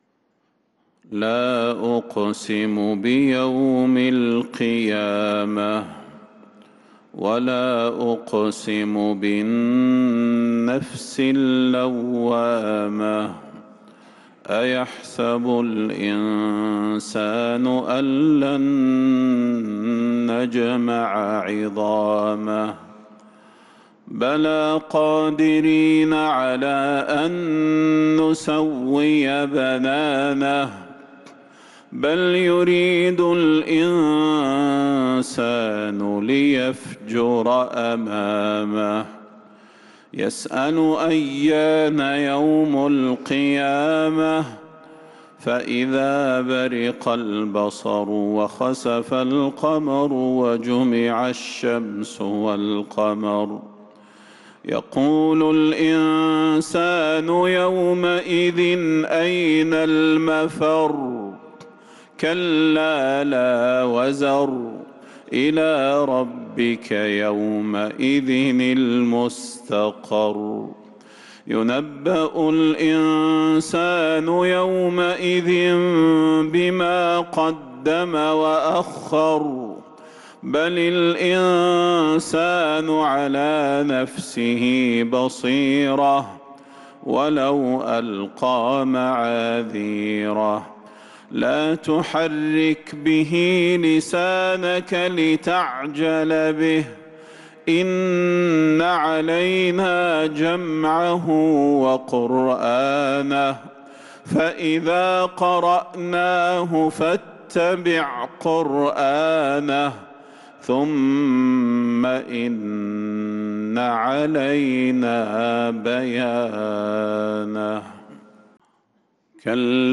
سورة القيامة | جمادى الأولى 1447هـ > السور المكتملة للشيخ صلاح البدير من الحرم النبوي 🕌 > السور المكتملة 🕌 > المزيد - تلاوات الحرمين